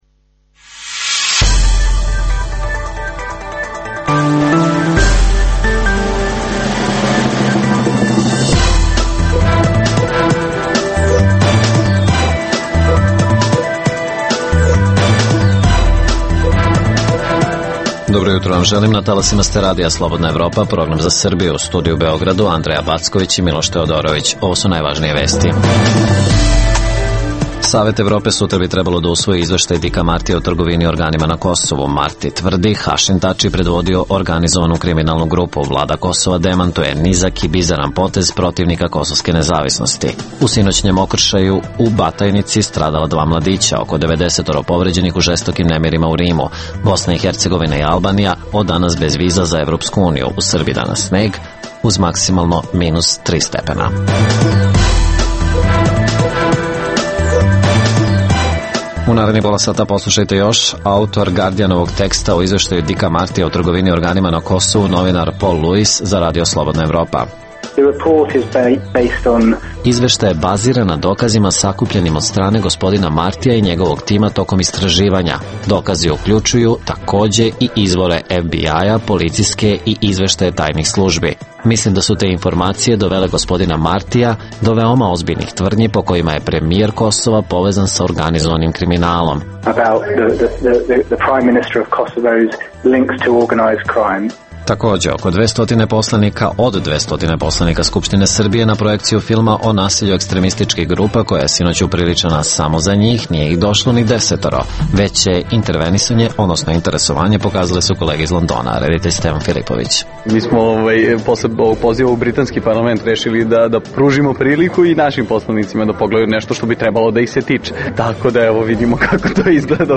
- Reportaža sa projekcije filma i Šišanje , koji se bavi ekstremnim navijačkim grupama u Srbiji, upriličenoj za 250 poslanika Skupštine Srbije, a na kojoj se pojavilo tek njih osam.